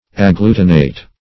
Agglutinate \Ag*glu"ti*nate\, v. t. [imp.